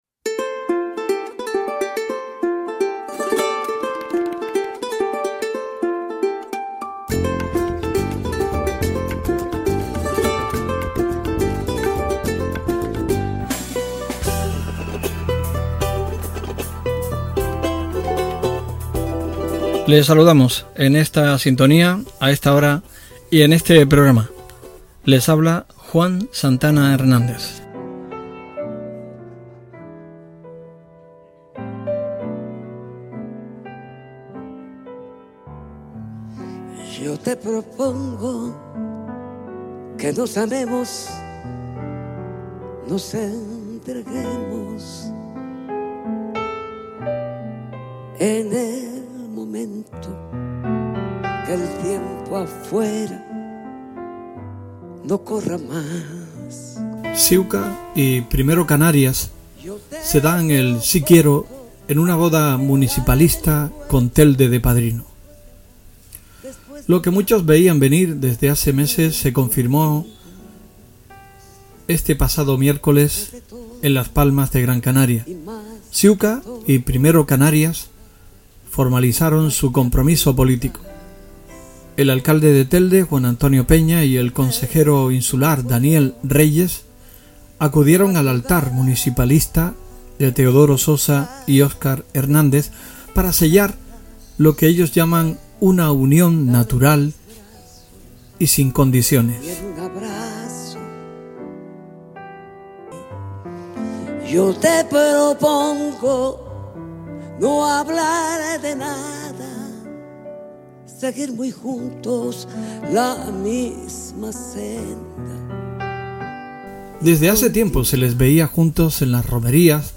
el exalcalde de Telde, Francisco Aureliano Santiago Castellano, ha repasado en una extensa entrevista su etapa al